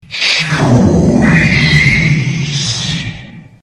Choese meme soundboard clip, short random vocal used for absurd humor, quick reactions, and chaotic meme buttons.